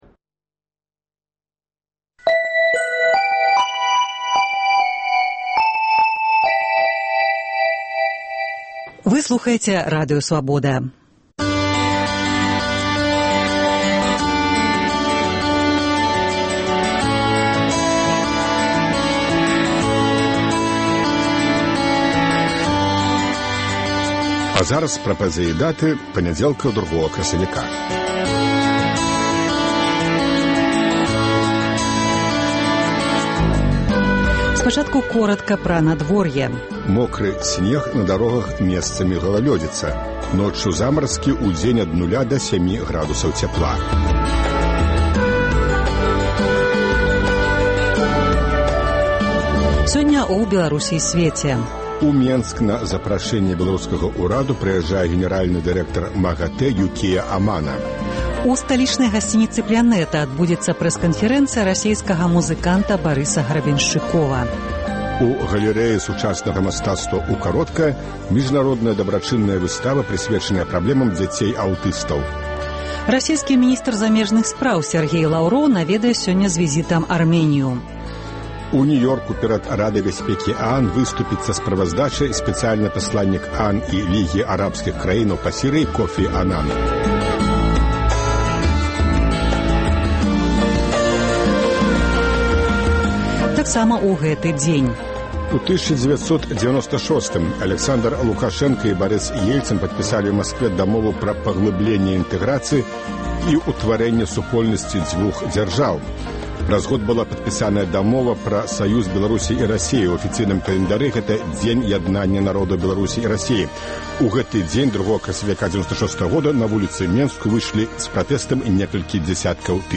Ранішні жывы эфір
Навіны Беларусі й сьвету, надвор'е, агляд друку, гутарка з госьцем, ранішнія рэпартажы, бліц-аналіз, музычная старонка